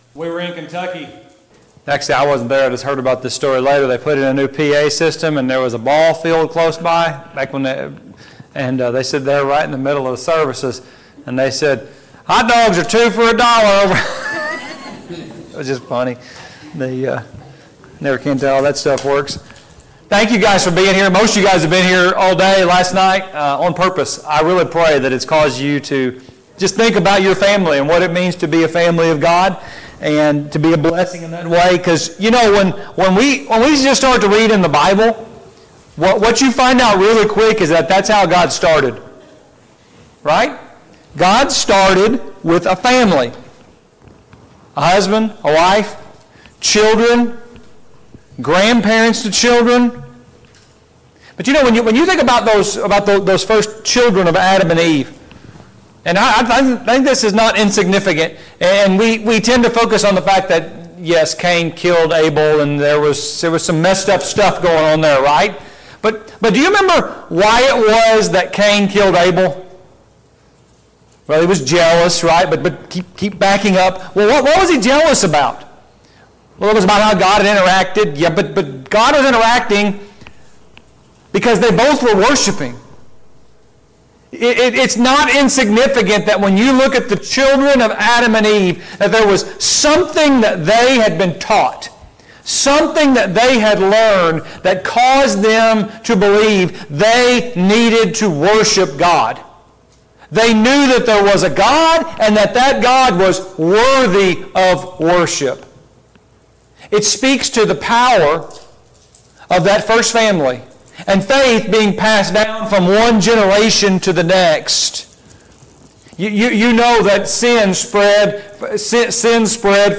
Marriage and Family Training Service Type: Gospel Meeting « 2.